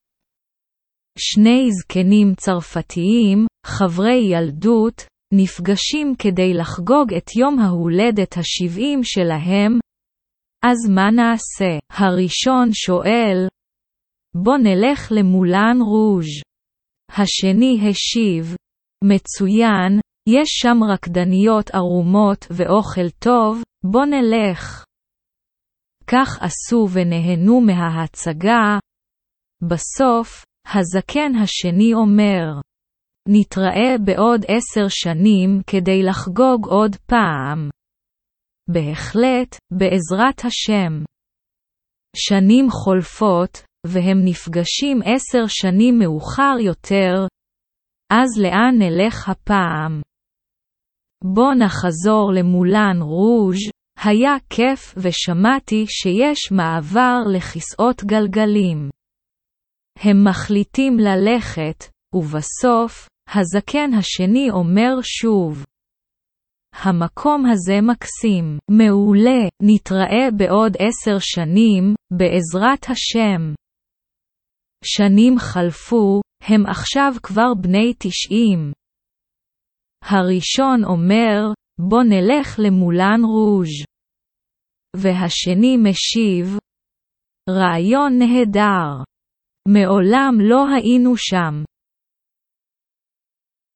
• Texte Hébreu lu à haute voix à un rythme lent !